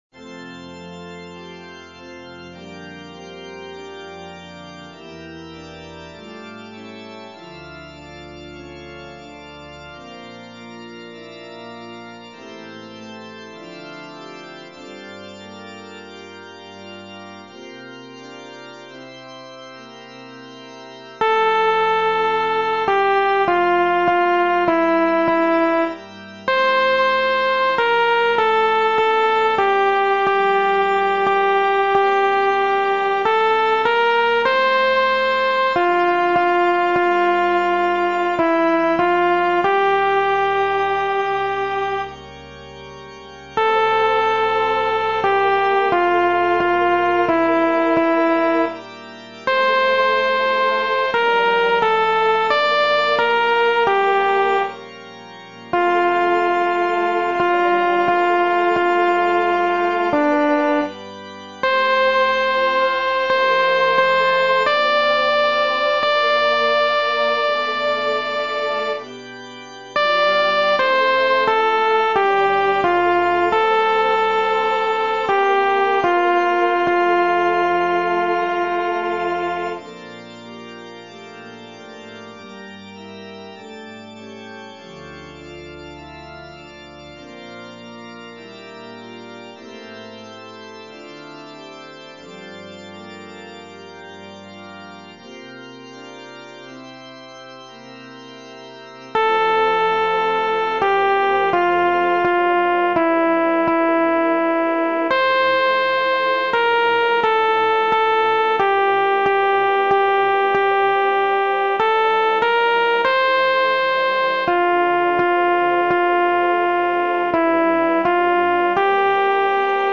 ソプラノ（フレットレスバス音）